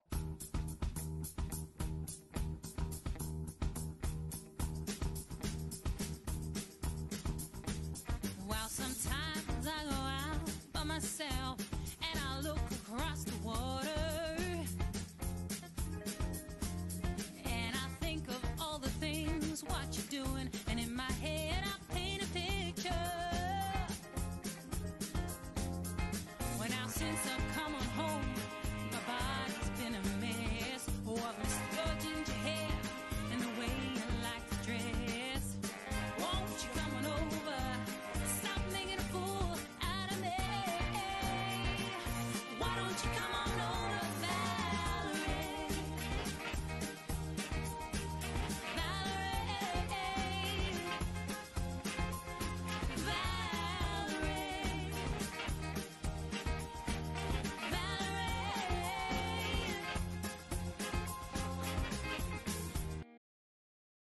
Dance Band Tracks